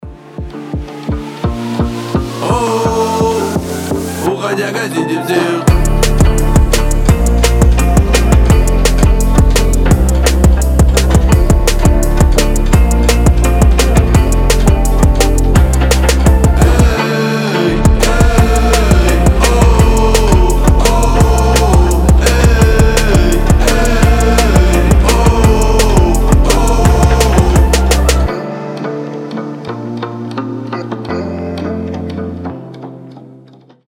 • Качество: 320, Stereo
гитара
Хип-хоп
мелодичные